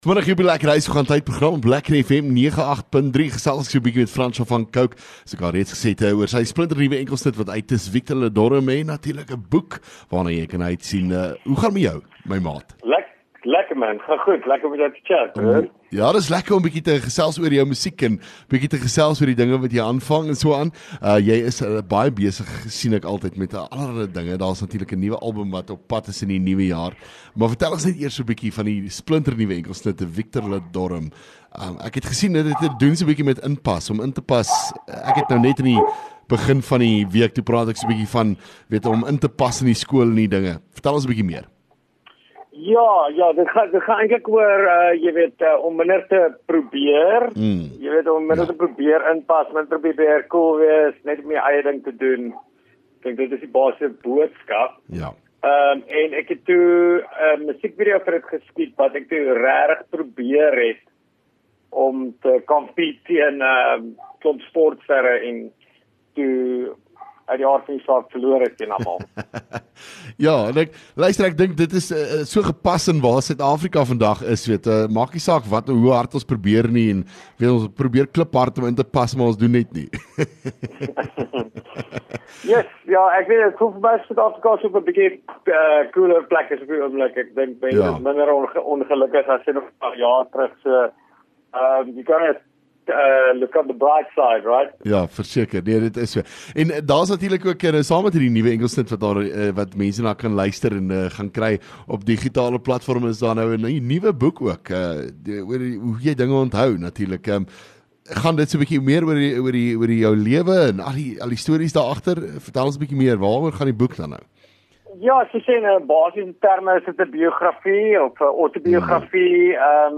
gesels met Francois Van Coke oor sy nuwe enkelsnit Victor Ludorum